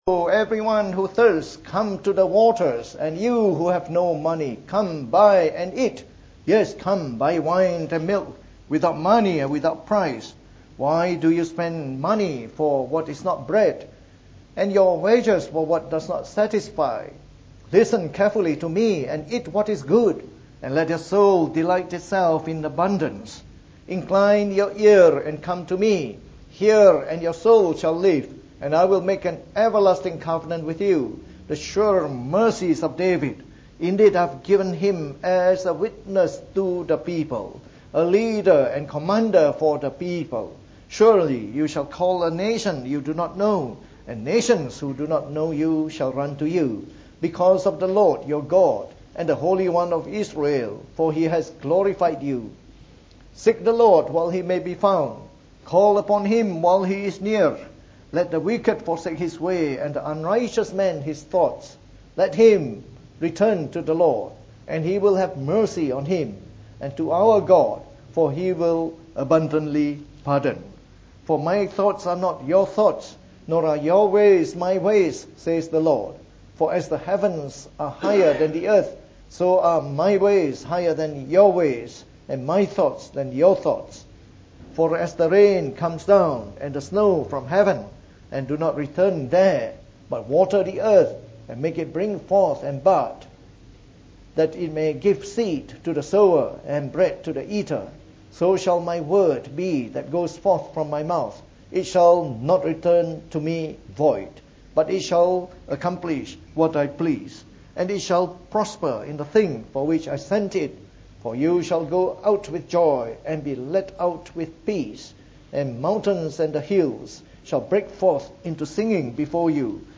From our series on the Book of Isaiah delivered in the Morning Service.